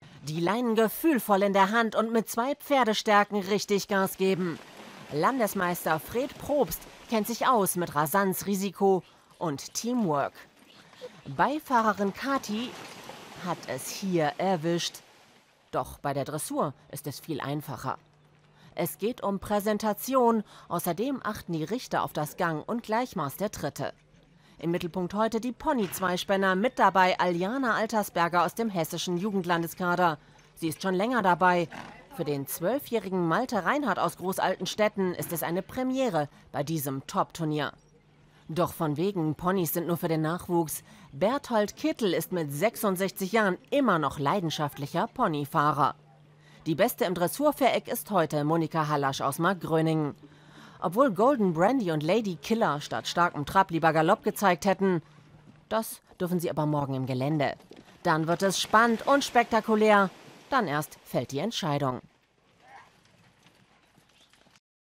SWR Bericht